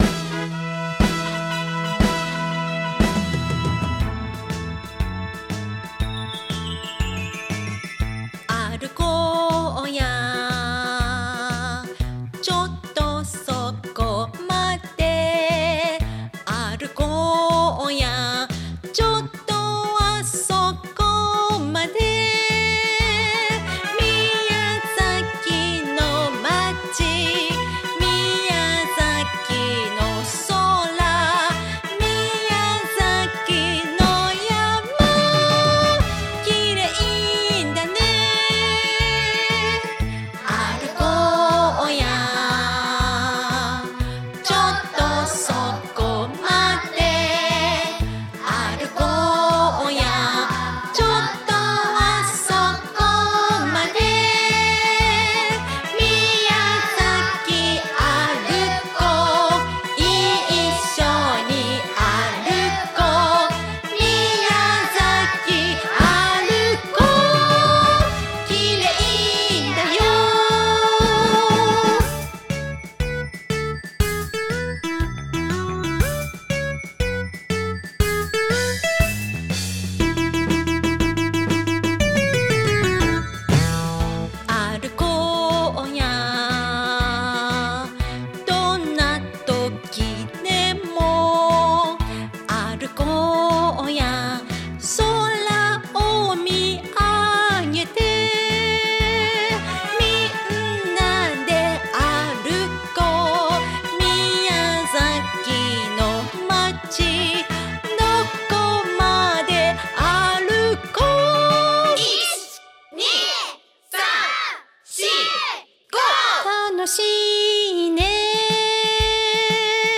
歩くのにぴったりのテンポの明るいメロディ♪「今日の一歩は、明日の元気！」
キッズキャラバン隊も参加した明るい歌声を聴きながら、宮崎の空の下、ちょっとそこまで歩きませんか？